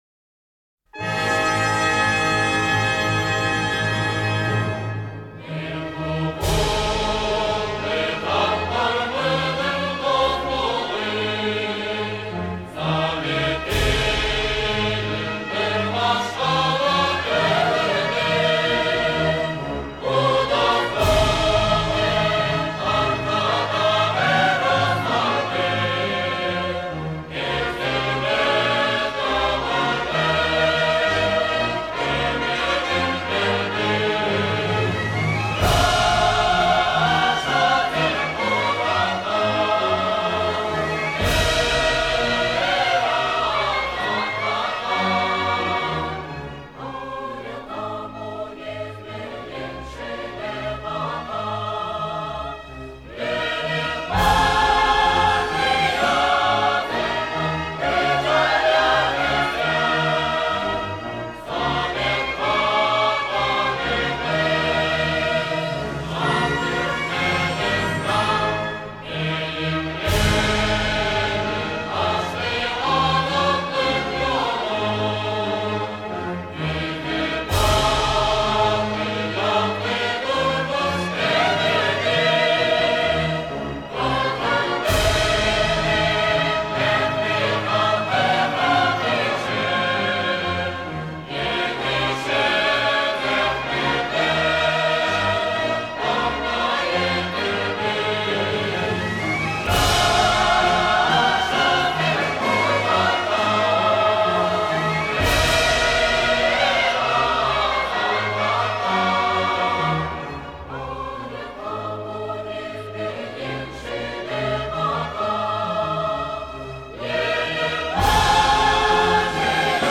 보컬 버전
미 해군 군악대, 1991년 이후 버전